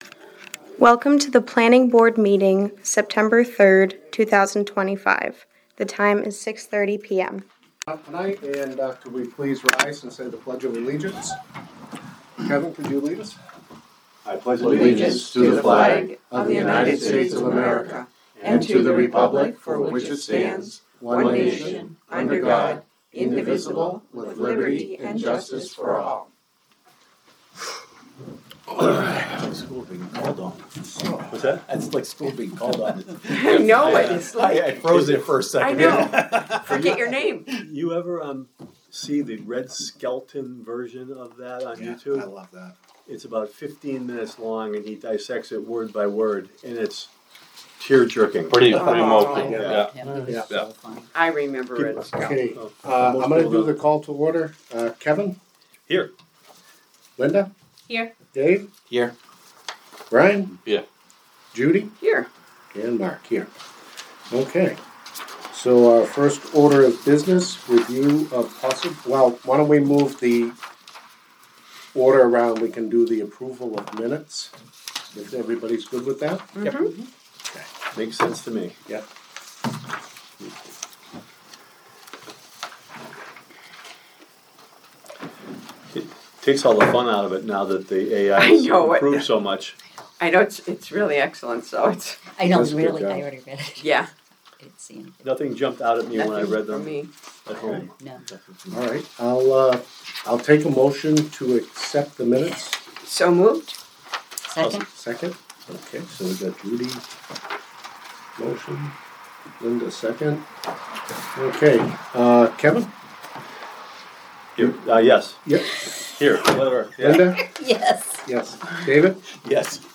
Audio recordings of committee and board meetings.
Planning Board Meeting